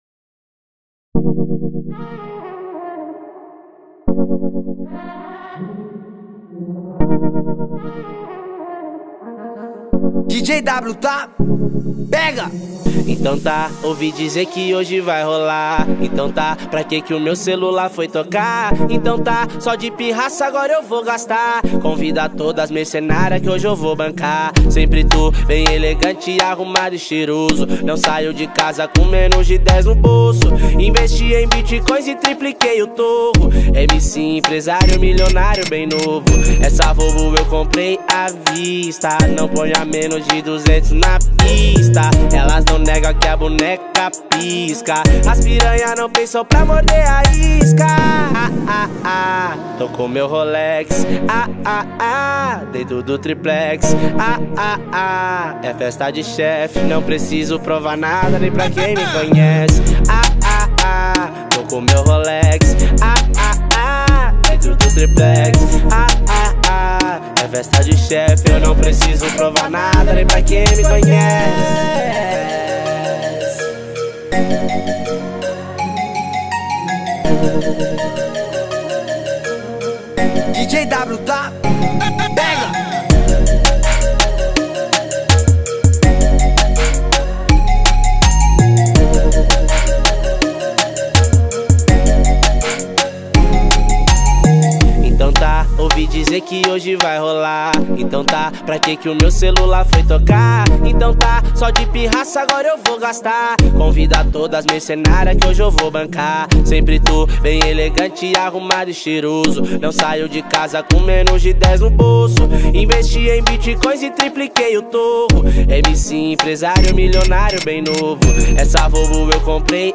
Baixar Funk